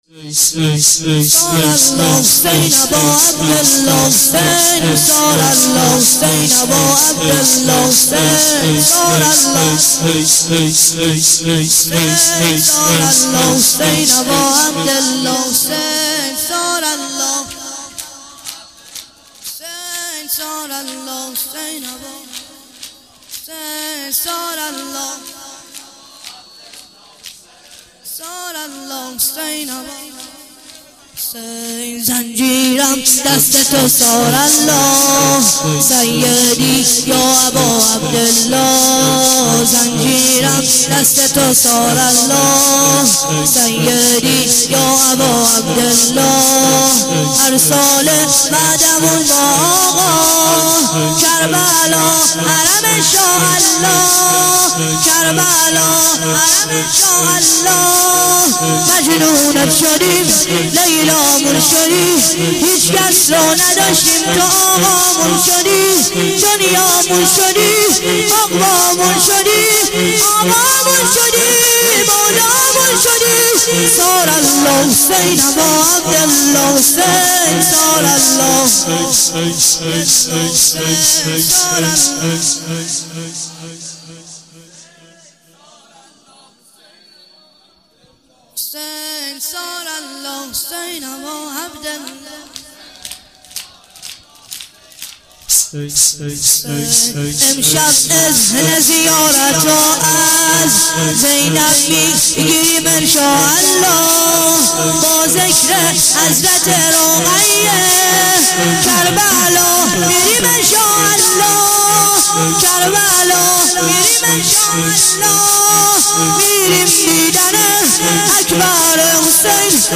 شور - زنجیرم دست تو ثارالله